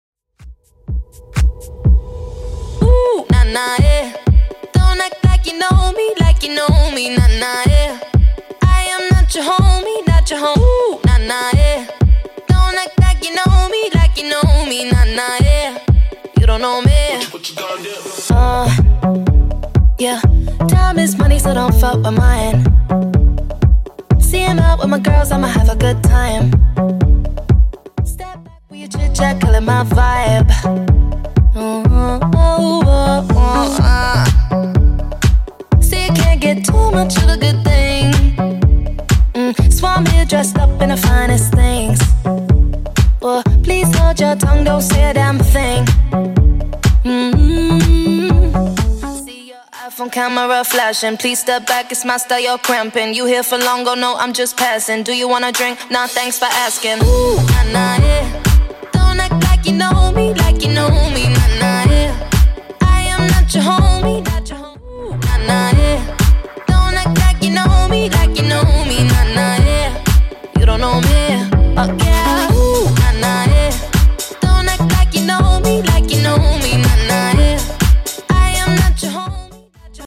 Genre: 70's Version: Clean BPM: 100